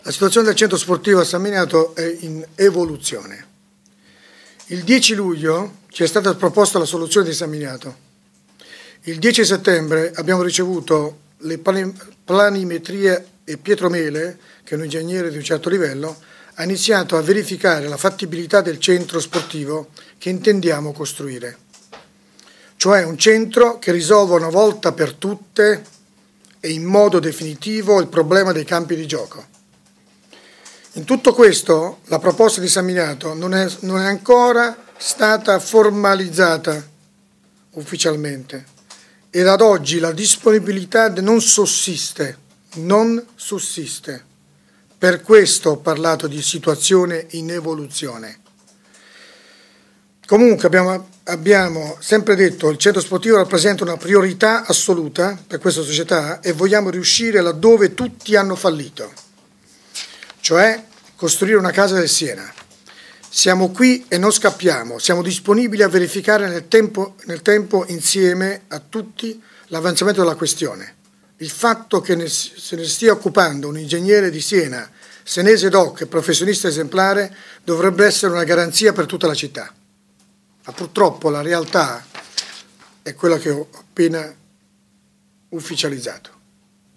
Di seguito gli audio della conferenza stampa